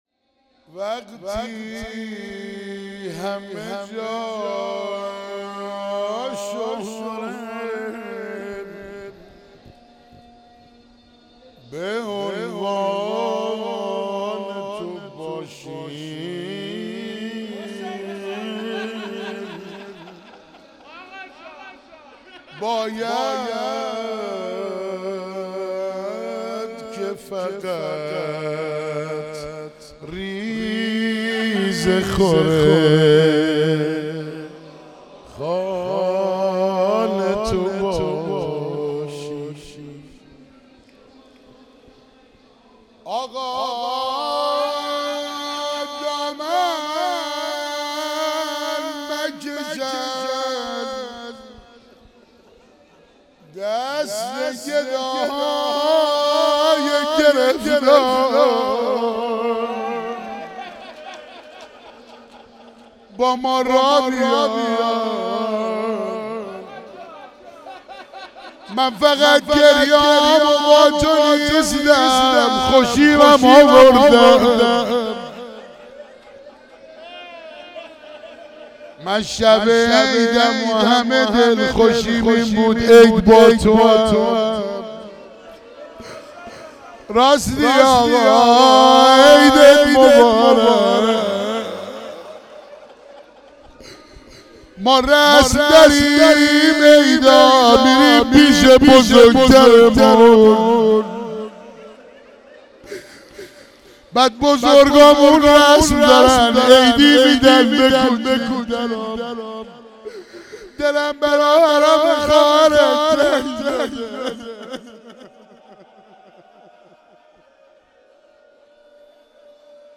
بهار علوی 97 کربلای معلی